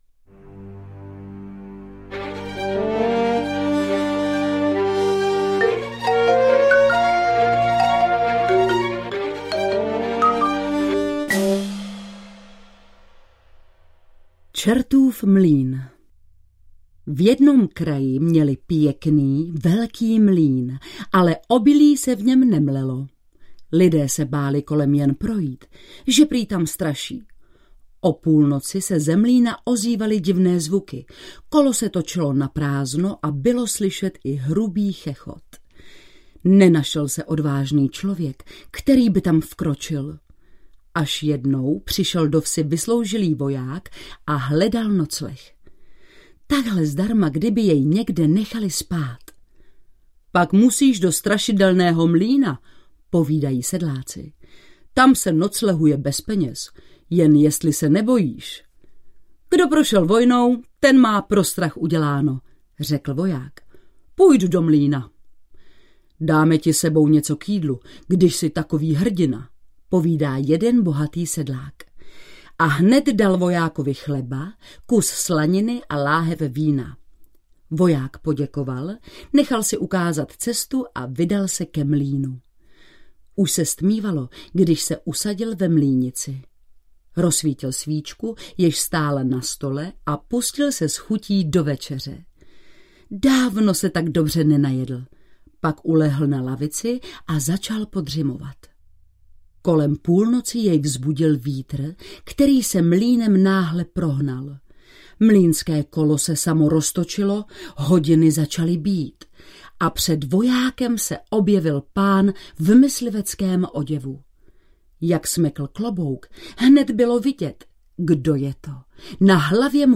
Národní pohádky pro malé děti audiokniha
Ukázka z knihy
• InterpretIlona Csáková